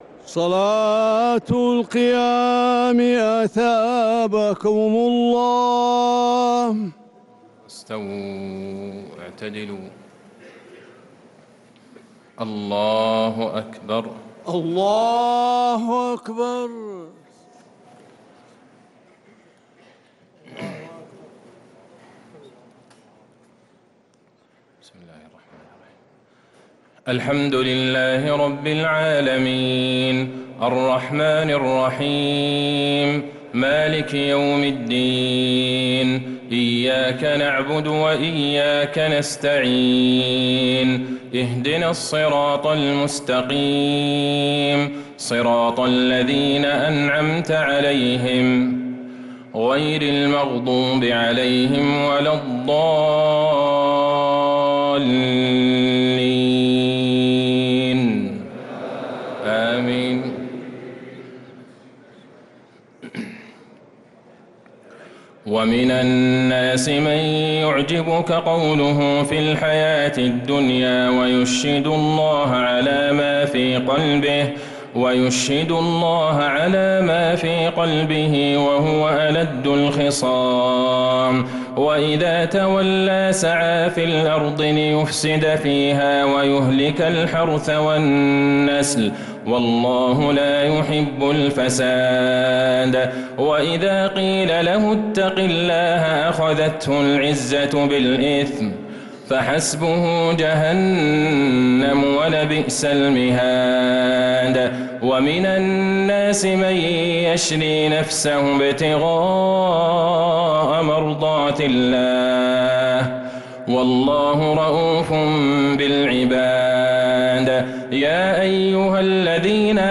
تراويح ليلة 3 رمضان 1446هـ من سورة البقرة {204-248} Taraweeh 3rd night Ramadan 1446H > تراويح الحرم النبوي عام 1446 🕌 > التراويح - تلاوات الحرمين